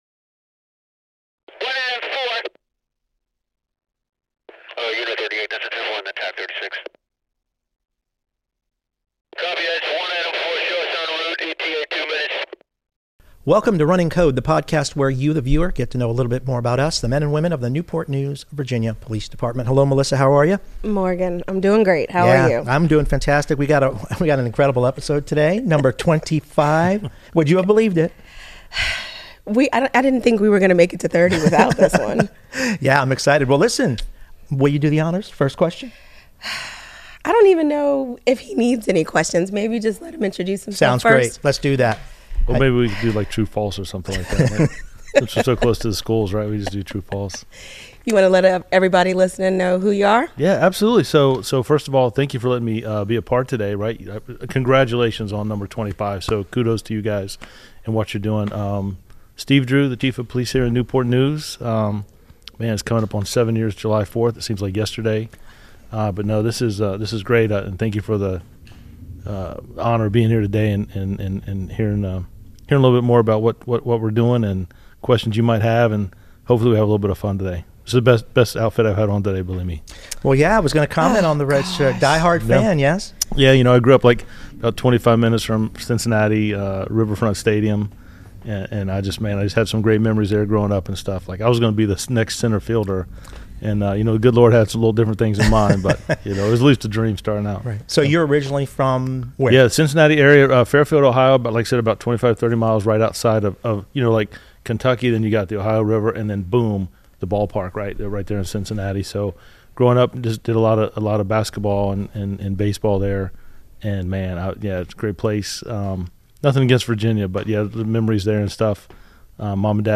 Chief Steve Drew is the guest for Episode 25 of Running Code. In his far-reaching conversation, Chief talks about his life growing up in Ohio, how his 30+ year career in law enforcement, his vision for NNPD and more.